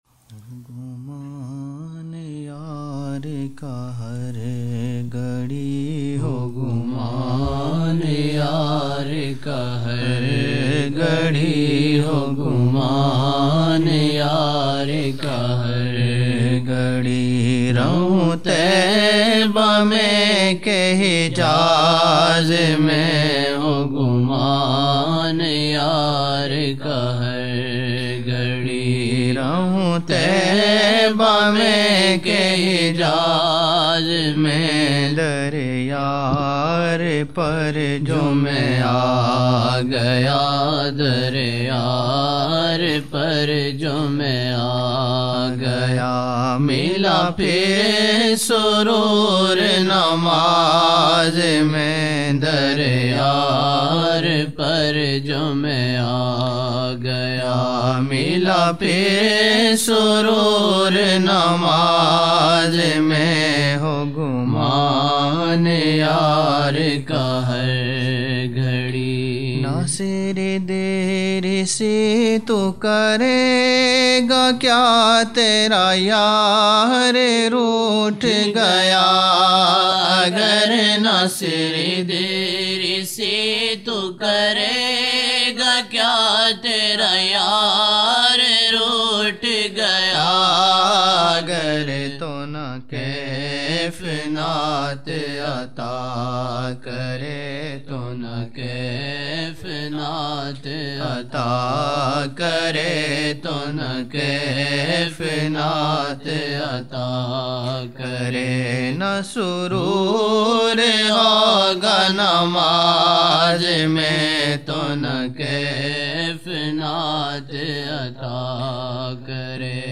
25 November 1999 - Maghrib mehfil (17 shaban 1420)
Naat shareef: - Na hatana apni nazrein ye mareez mar na jaye - Lam yaati naziro kafi nazarin, misl e tau na shud payda jana Previous Next Download Now